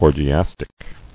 [or·gi·as·tic]